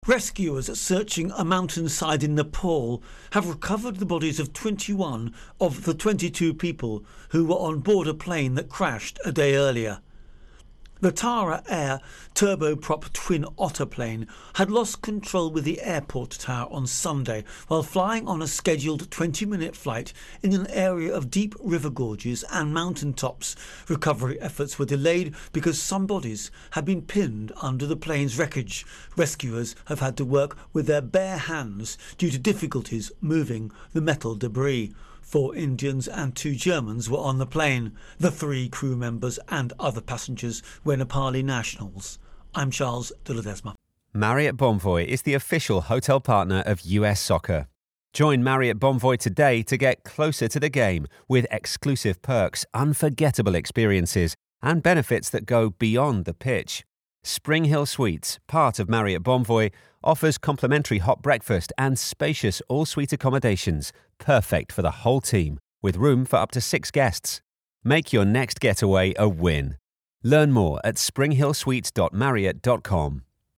Nepal Plane Crash Intro and Voicer